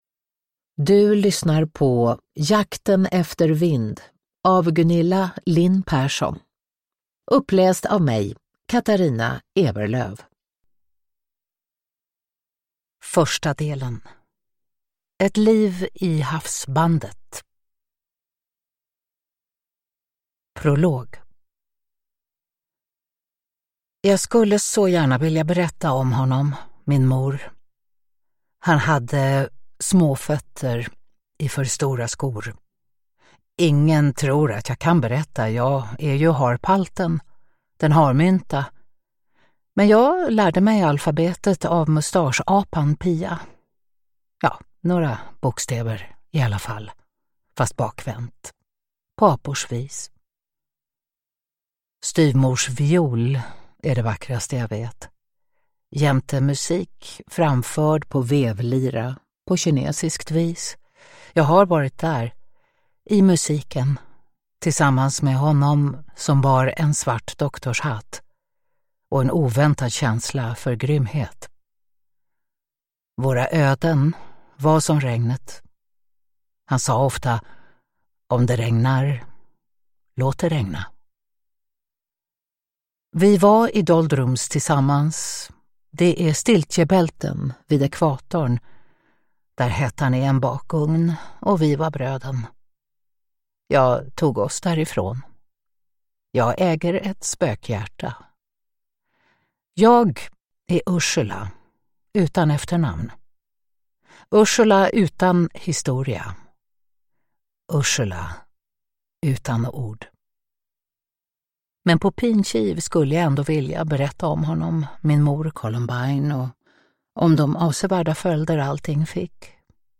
Uppläsare: Katarina Ewerlöf
Ljudbok